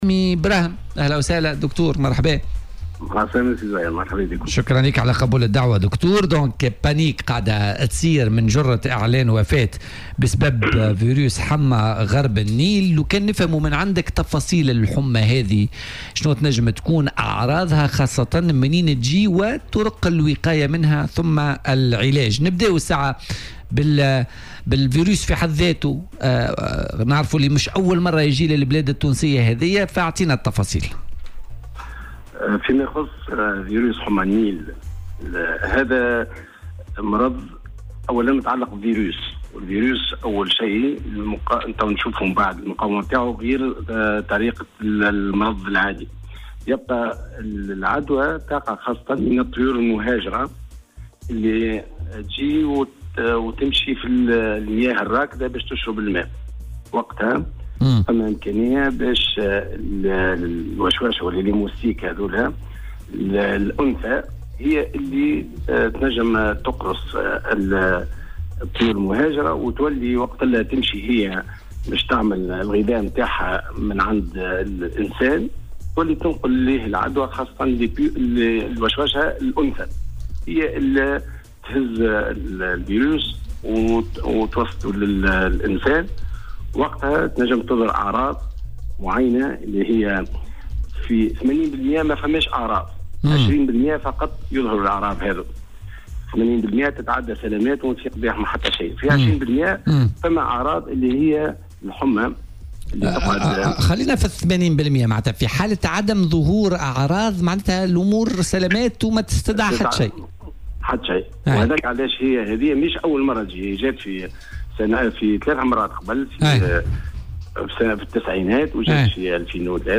طبيب يشرح أعراض فيروس حمّى غرب النيل ومدى خطورته
وقال في مداخلة هاتفية مع "بوليتيكا" على "الجوهرة أف أم" إن 80 % من الحالات تمر دون مضاعفات وعادة ما تظهر أعراضه بعد 6 أيام من العدوى وعندها يجب التوجه فورا إلى أقرب طبيب أو مستوصف للقيام بالتحاليل اللازمة والتأكد من الفيروس. وأضاف أن الوقاية تكون بالرش بالمبيدات بأماكن المياه الراكدة ونظافة المنزل وتجنب اللعب قرب المياه الراكدة بالنسبة للأطفال.